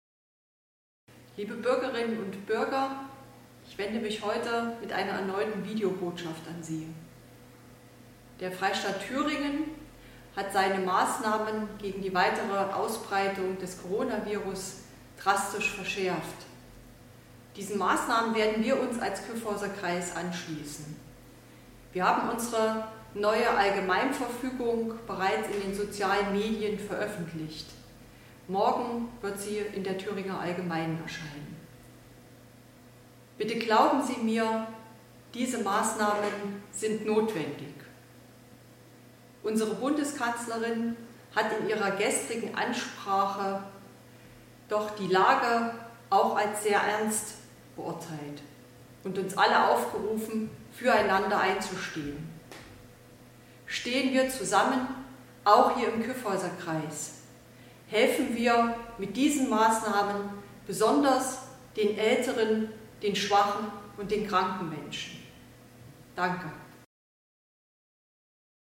Die 7. Videobotschaft der Landrätin des Kyffhäuserkreises, Antje Hochwind-Schneider (SPD) befasst sich mit der Thematik öffentliche Einschränkungen im Kyffhäuserkreis: